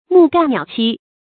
木干鸟栖 mù gàn niǎo qī 成语解释 谓鸟栖树上，至树干枯也不离去。